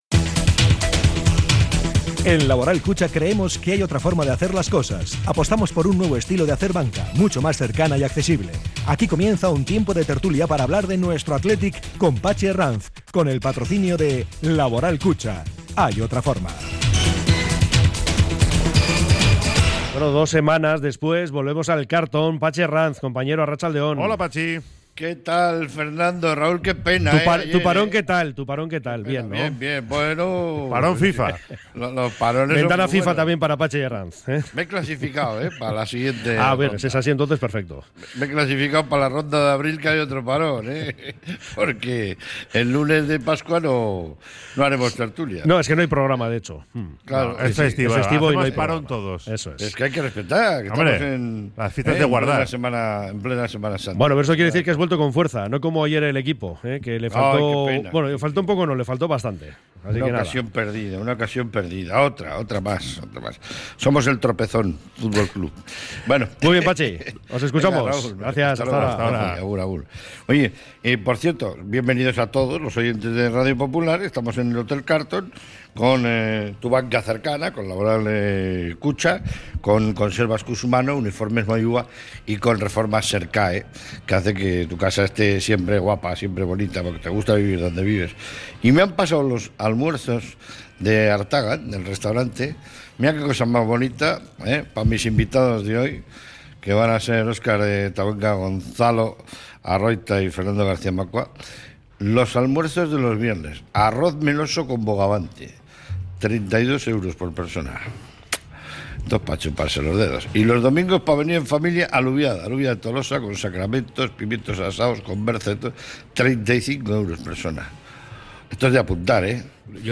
sus invitados desde el Hotel Carlton